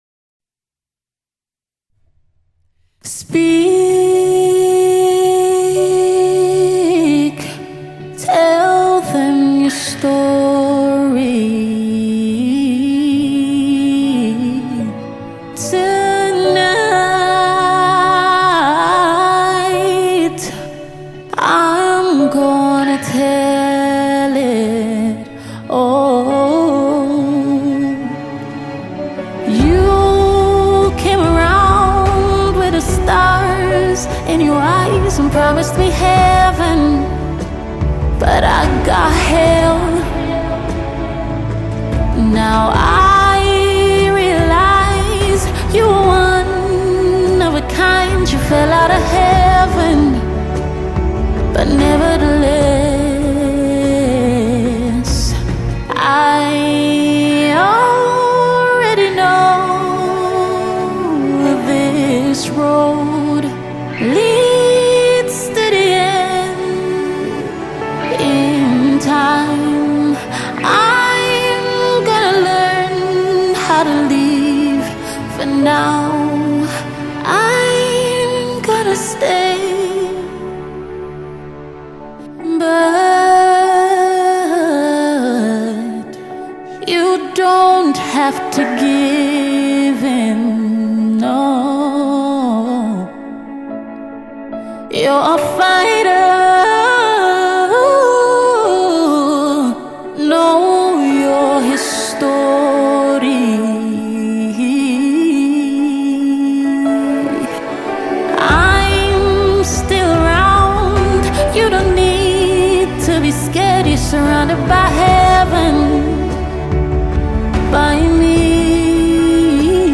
March 5, 2025 Publisher 01 Gospel 0